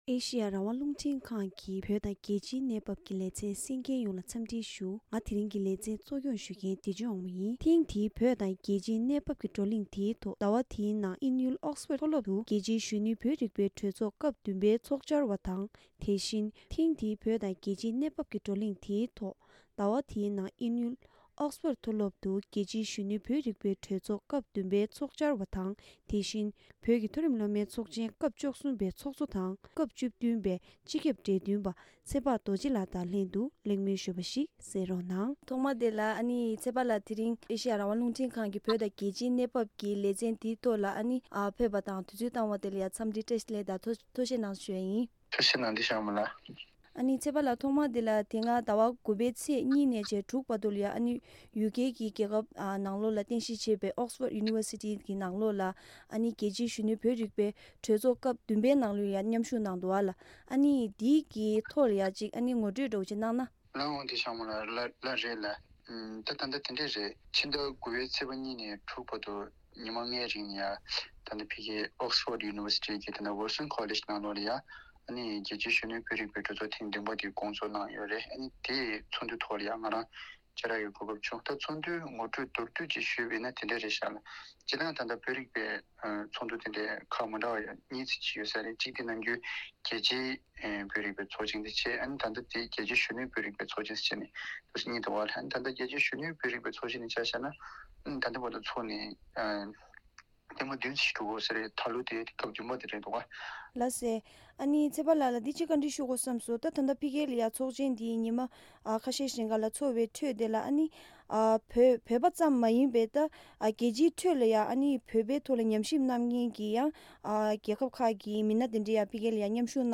ཟླ་བ་དེའི་ནང་ཨིན་ཡུལ་Oxford མཐོ་སློབ་དུ་རྒྱལ་སྤྱིའི་གཞོན་ནུའི་བོད་རིག་པའི་གྲོས་ཚོགས་སྐབས་བདུན་པའི་ཐོག་གླེང་མོལ་ཞུས་པ།